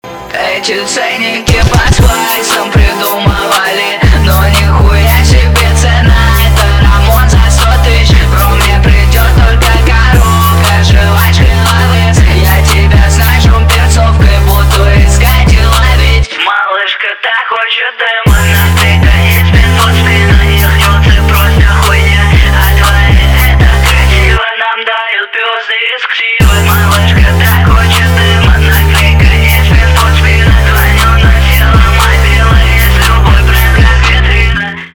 русский рэп